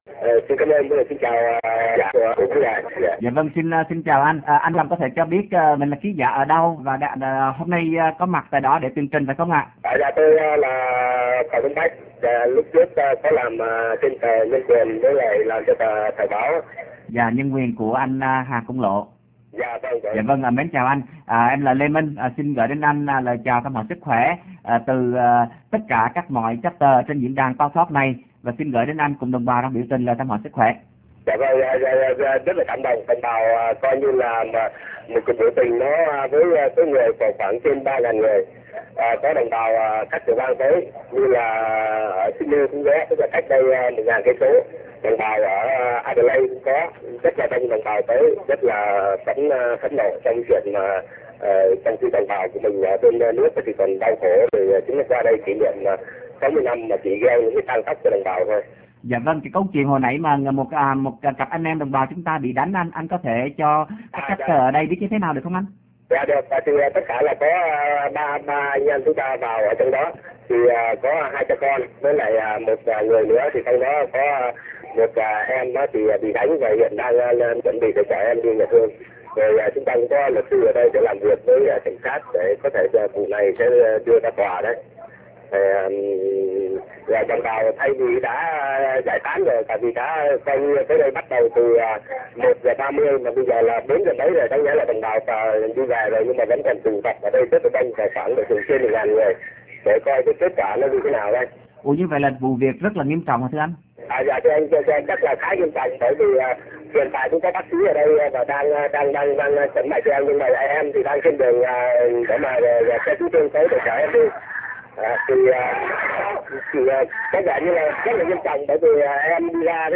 Xin qu� vị chờ trong gi�y l�t sẽ nghe được �m thanh từ cuộc biểu t�nh (phần 1)
Melbourne1.wma